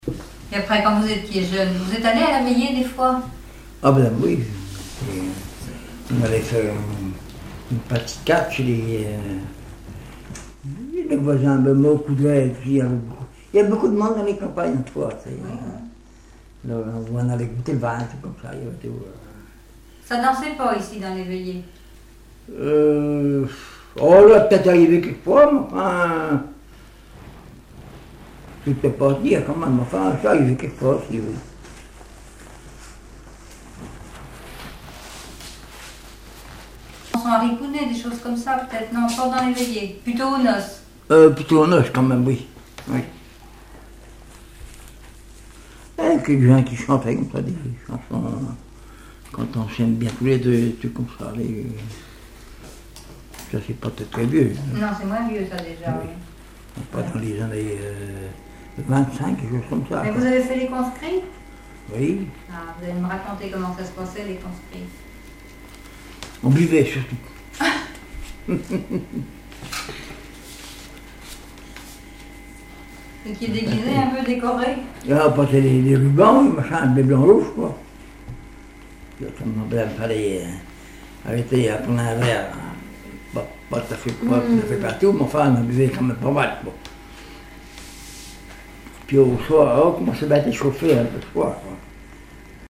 Témoignages et chansons
Catégorie Témoignage